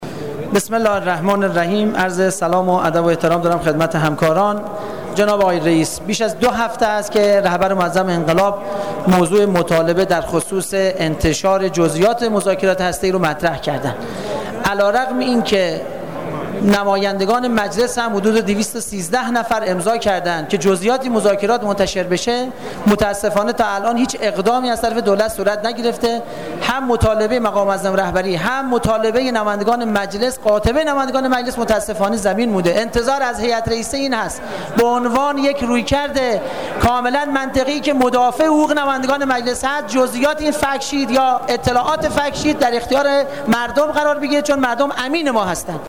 جلسه علنی ۱ اردیبهشت ۹۴
صوت نطق محمدرضا پورابراهیمی ، نماینده کرمان در خصوص انتشار گزاره برگ ایرانی را از طریق کادر زیر بشنوید.